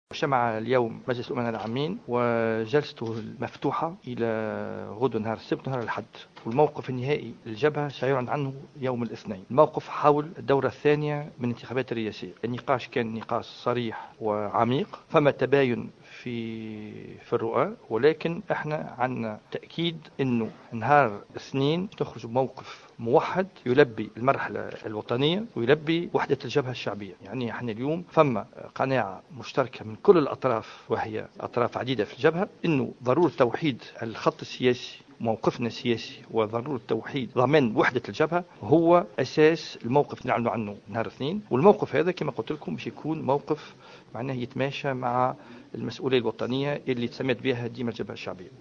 lors d’une conférence de presse